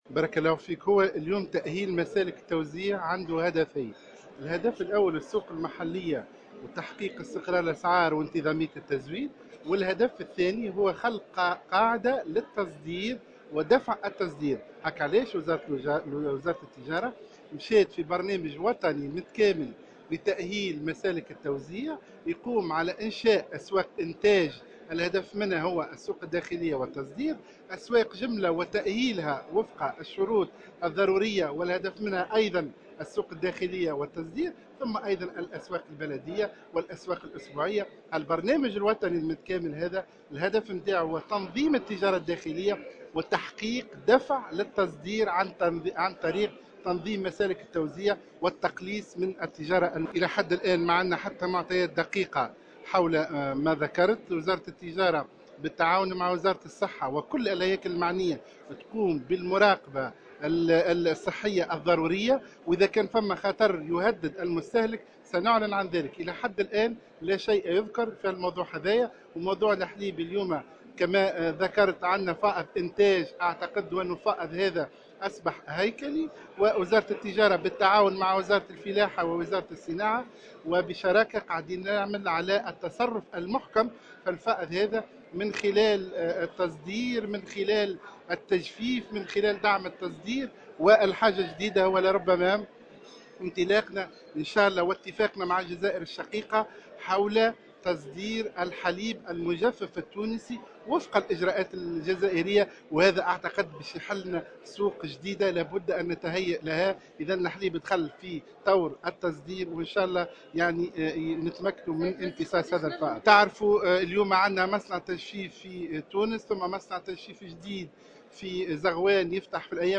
أكد وزير التجارة محسن حسن في تصريح إعلامي، اليوم الأربعاء، أنه لا وجود لأية معطيات دقيقة حول انتشار جرثومة السل في الحليب في تونس، مشيرا إلى أن وزارة التجارة تقوم بالمراقبة الصحية الضرورية بالتعاون مع وزارة الصحة وكافة الهياكل المعنية.